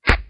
flesh Impact Bullet Sound.wav